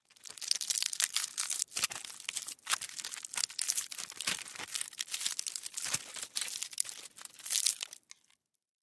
Звуки фольги - скачать и слушать онлайн бесплатно в mp3
Фольга Ещё